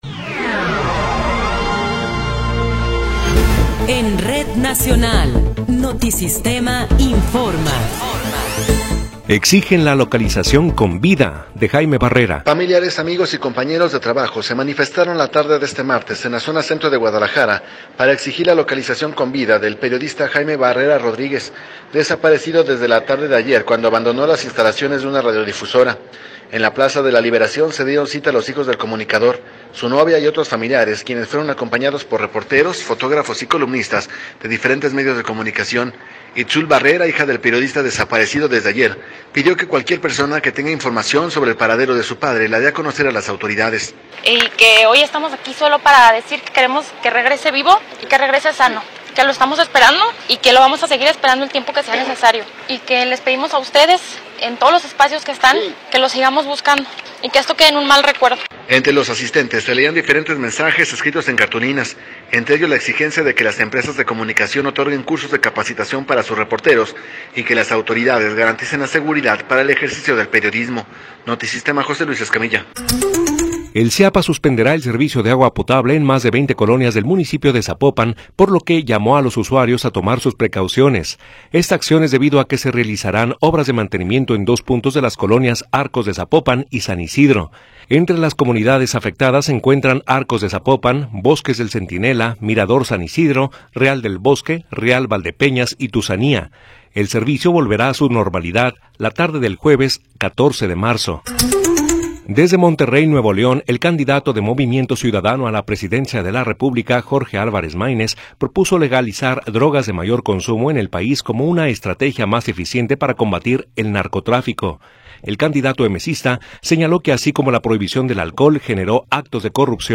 Noticiero 19 hrs. – 12 de Marzo de 2024
Resumen informativo Notisistema, la mejor y más completa información cada hora en la hora.